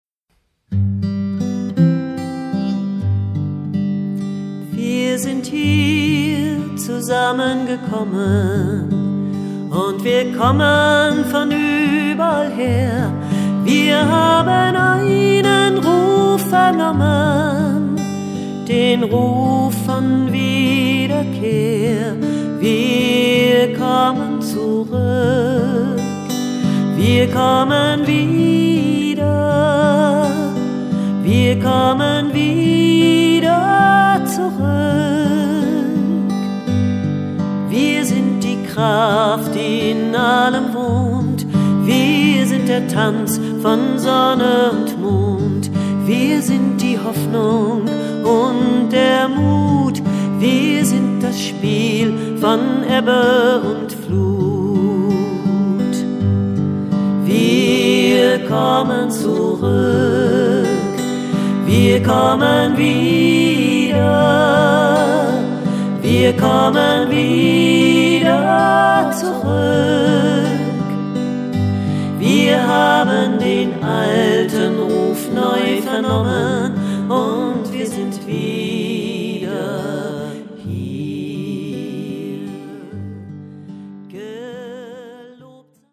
Schwungvolle, meditative  und einfuehlsame Lieder und Gebete